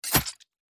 attack_hit_3.mp3